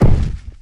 crossbow_hit1.wav